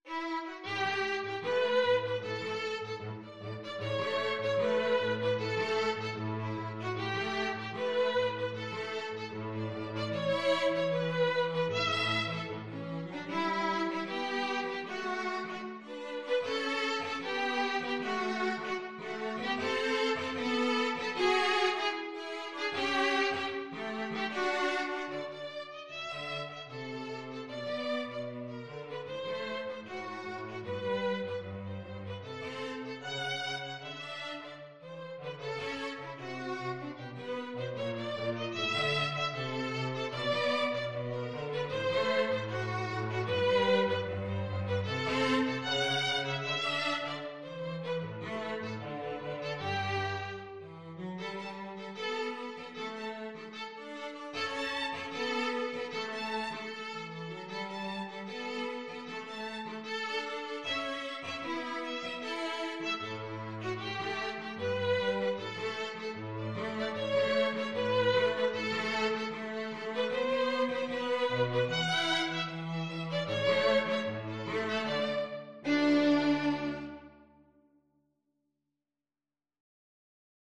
Classical (View more Classical Violin-Cello Duet Music)
Brazilian